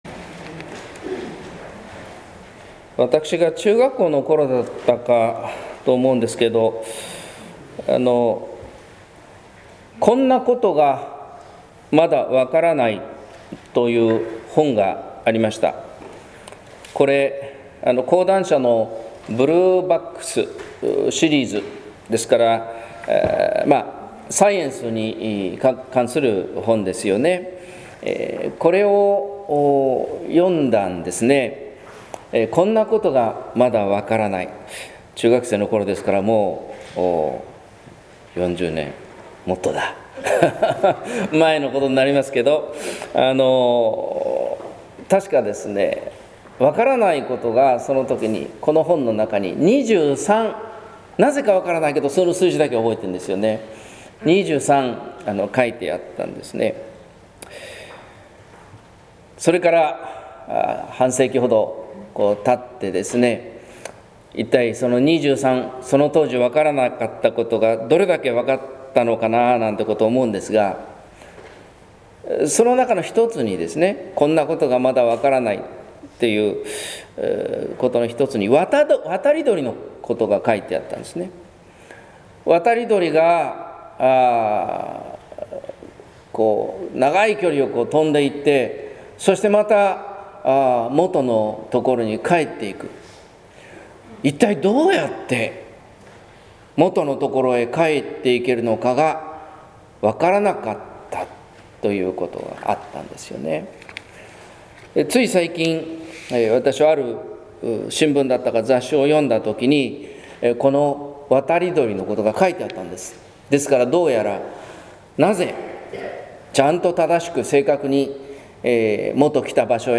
説教「これらのことを話したのは・・・」（音声版） | 日本福音ルーテル市ヶ谷教会
聖霊降臨祭